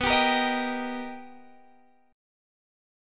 Listen to B+M7 strummed